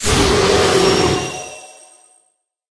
Cri d'Amovénus dans sa forme Totémique dans Pokémon HOME.
Cri_0905_Totémique_HOME.ogg